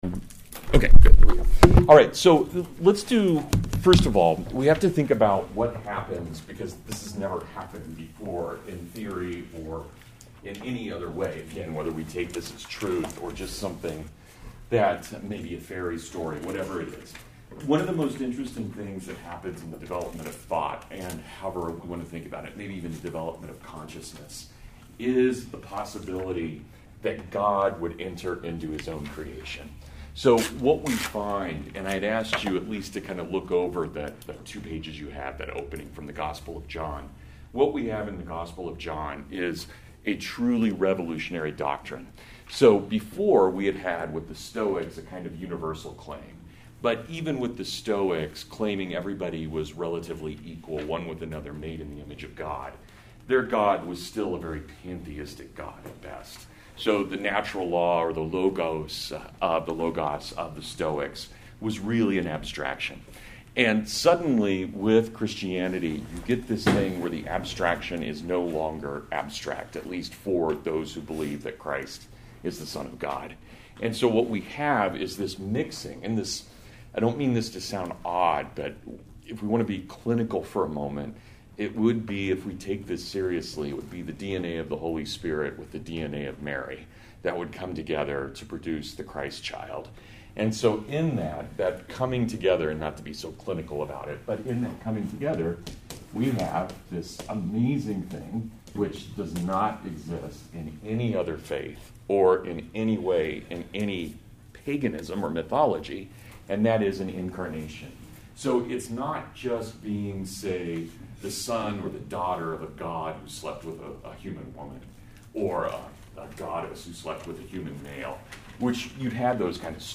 Lecture: What Has Athens to do with Jerusalem